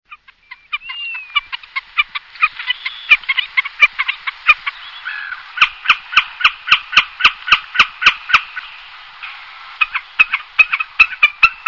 beccaccino c.wav